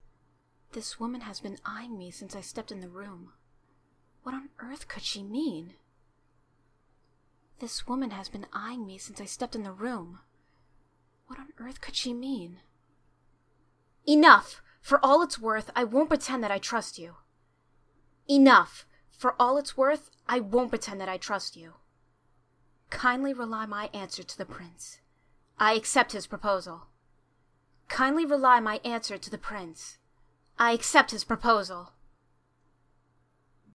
Voice Example: